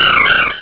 pokeemerald / sound / direct_sound_samples / cries / jirachi.aif